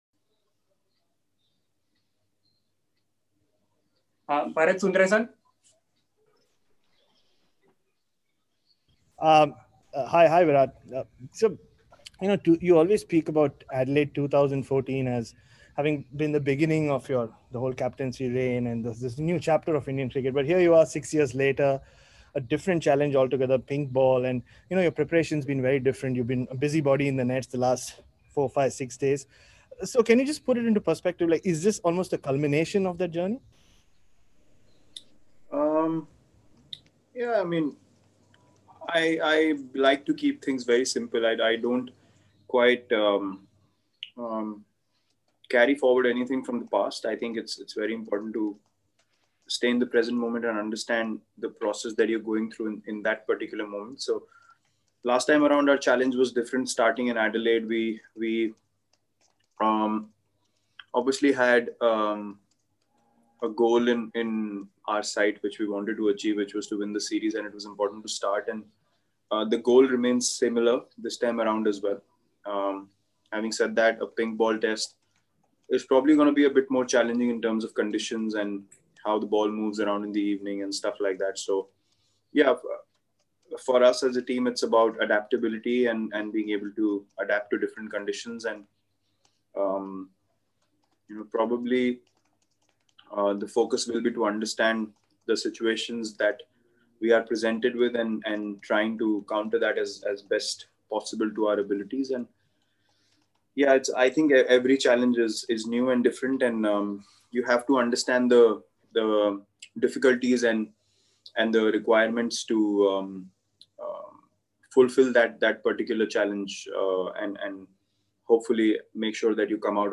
Virat Kohli addressed a virtual press conference ahead of the first Border-Gavaskar Test against Australia in Adelaide.